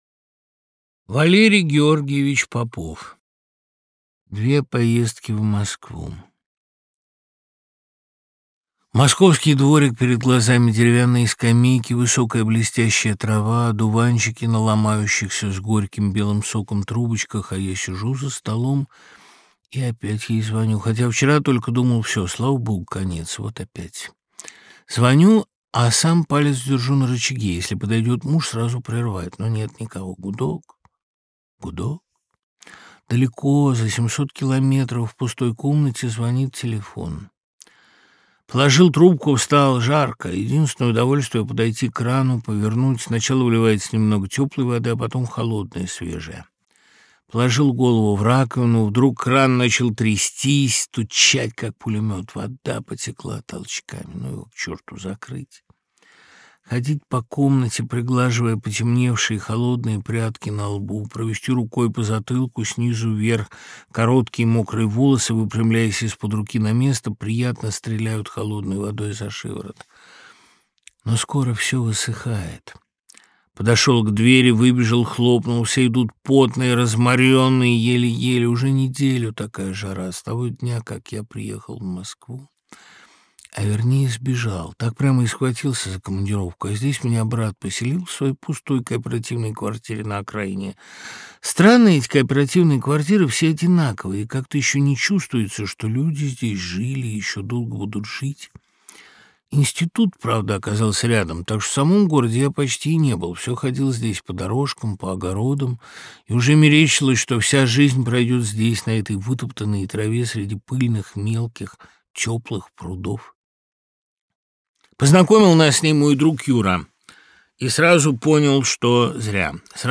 Аудиокнига Две поездки в Москву. Повести и рассказы в исполнении Дмитрия Быкова + Лекция Быкова Д. | Библиотека аудиокниг
Повести и рассказы в исполнении Дмитрия Быкова + Лекция Быкова Д. Автор Дмитрий Быков Читает аудиокнигу Дмитрий Быков.